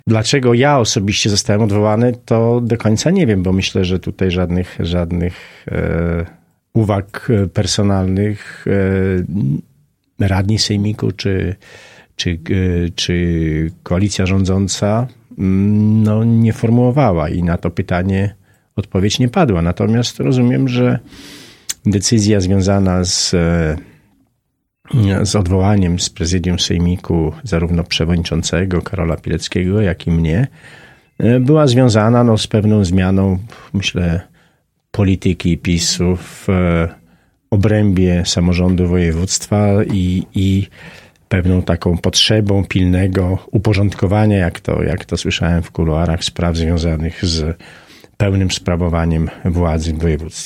W poniedziałek ze stanowiska wiceprzewodniczącego sejmiku został odwołany Cezary Cieślukowski. W czwartek (21.02.19) był gościem Radia 5.